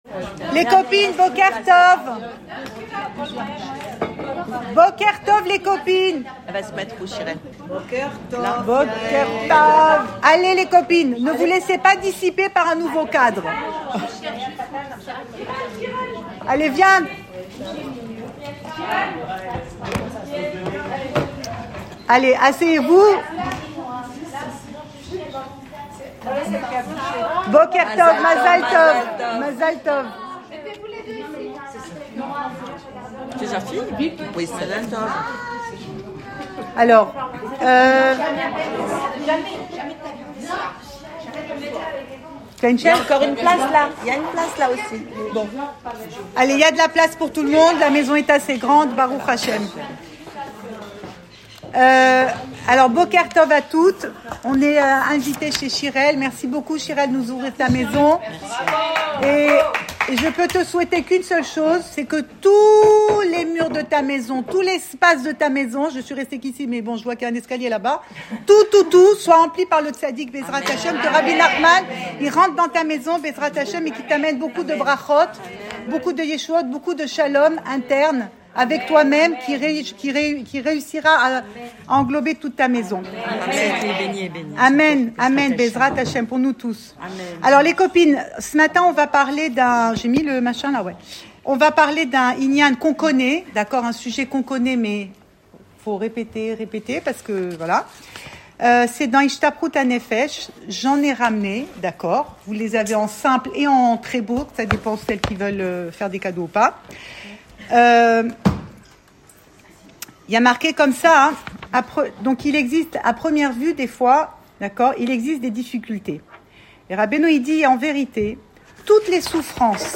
Cours audio Emouna Le coin des femmes Le fil de l'info Pensée Breslev - 18 décembre 2024 20 décembre 2024 Tout est pour le bien : alors pourquoi prier ? Enregistré à Tel Aviv